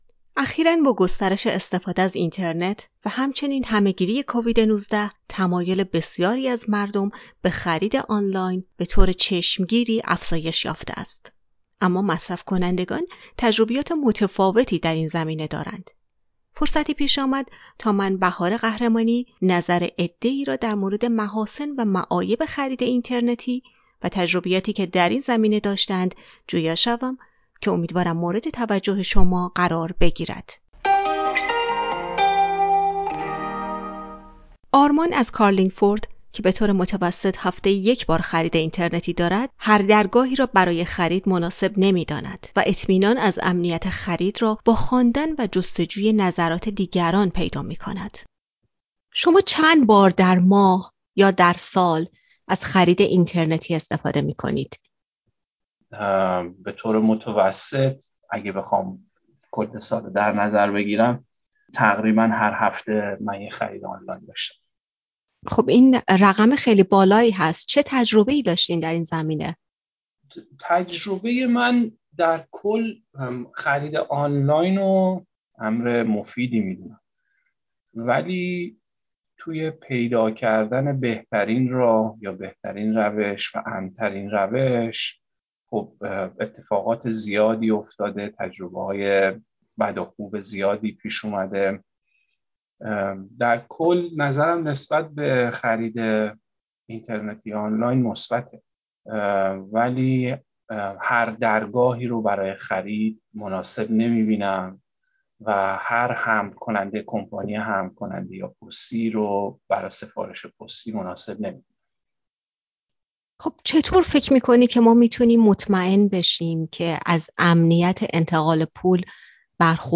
فرصتی پیش آمد تا نظر عده ای را در مورد محاسن و معایب خرید اینترنتی و تجربیاتی که در این زمینه داشته اند، جویا شویم.